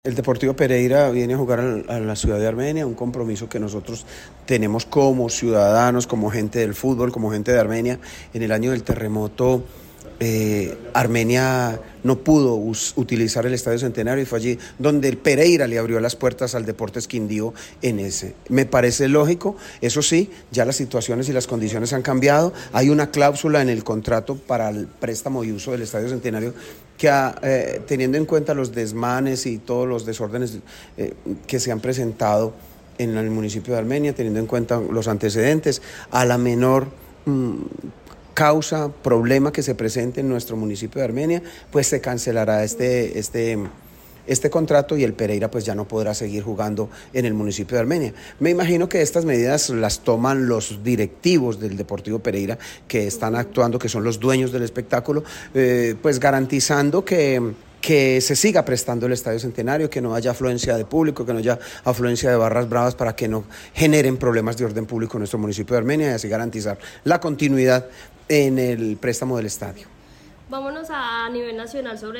Alcalde de Armenia, James Padilla García, partido viernes 16 de enero